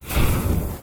finallanding.wav